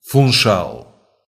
Funchal (Portuguese pronunciation: [fũˈʃal]